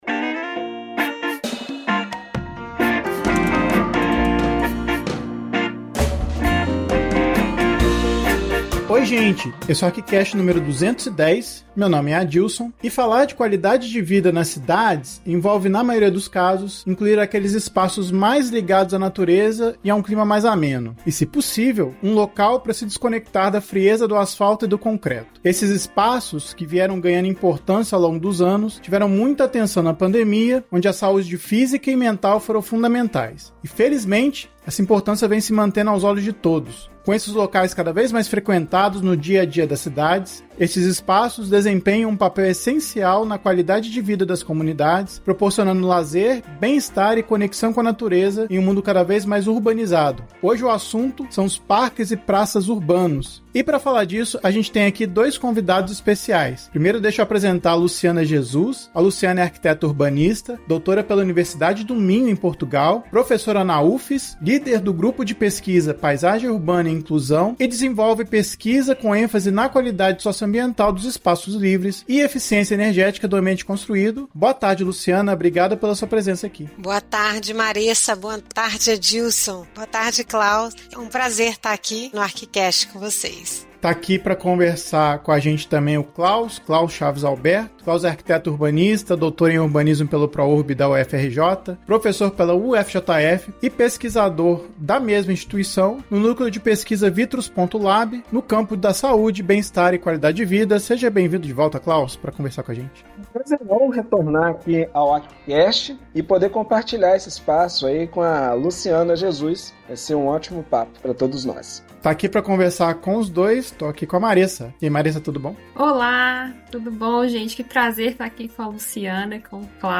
A discussão é enriquecida por dois convidados especiais